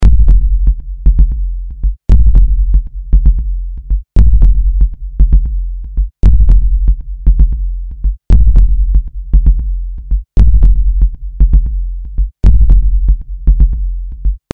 In Pad 4 legen wir gleich acht Bassdrums als Layer.
Hier ist nur die Bassdrum von Pad 4 mit ihren Layern beteiligt: